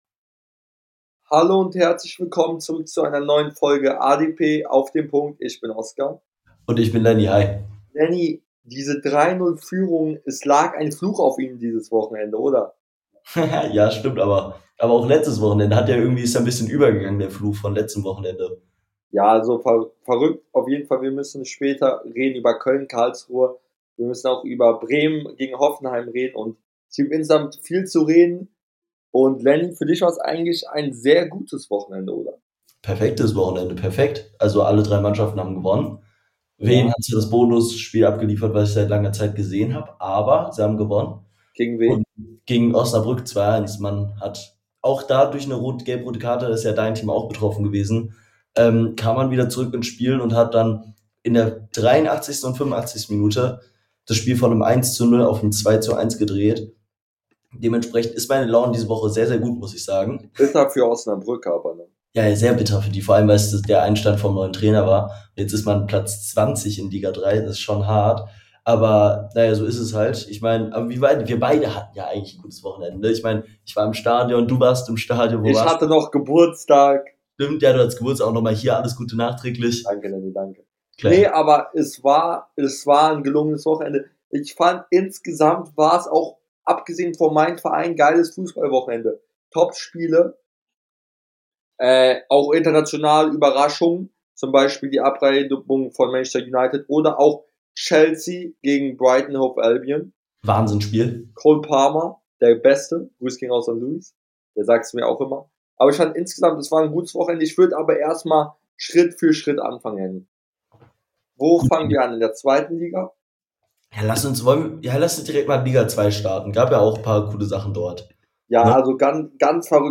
In der heutigen Folge reden die beiden Hosts über Karlsruhes und Bremens Aufholjagd , Cole Palmers Viererpack und vieles mehr